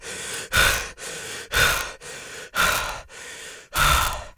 Panting Male
Panting Male.wav